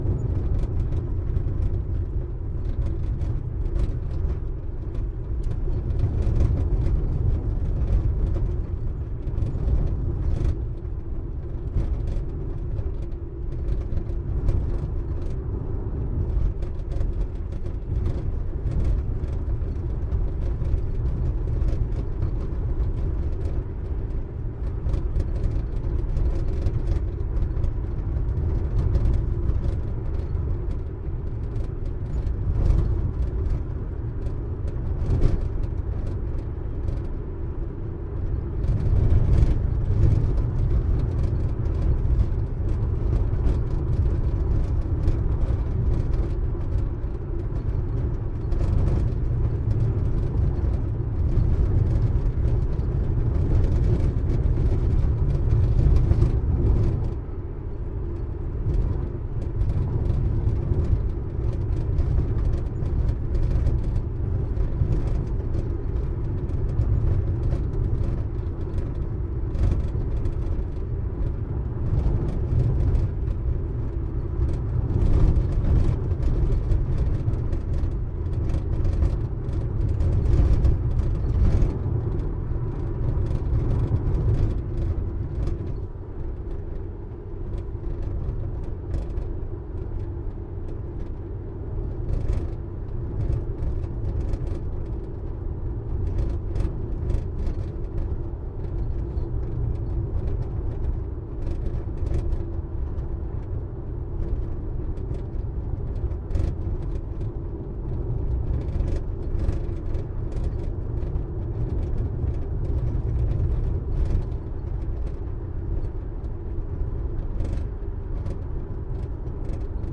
哥伦比亚 " 汽车 卡车 厢式车 中速行驶 颠簸的土路2
描述：汽车卡车面包车驾驶中速颠簸土路2
标签： 速度 颠簸 卡车 汽车 INT 驾驶 货车 中型
声道立体声